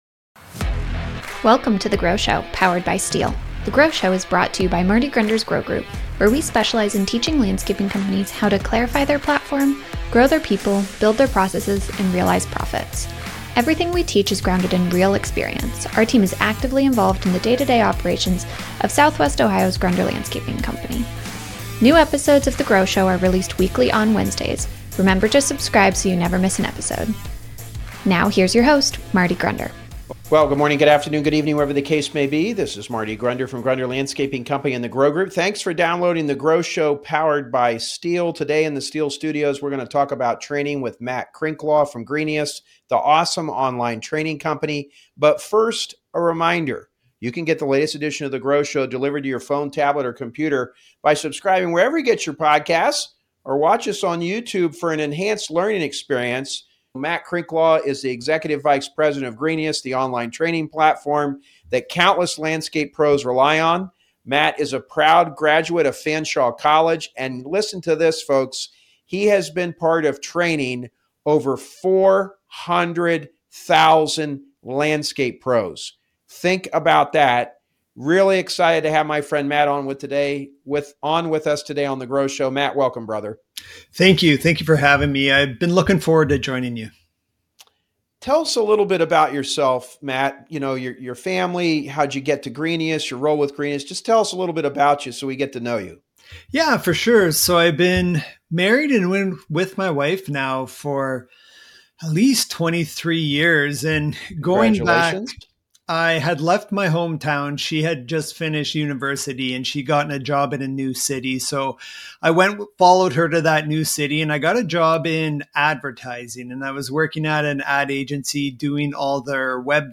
Interview Series: Training Your Team for Success